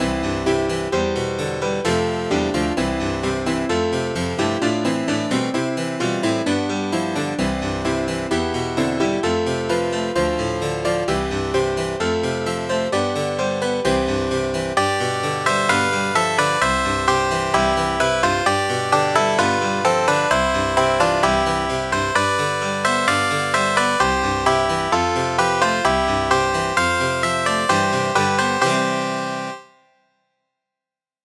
Converted from .mid to .ogg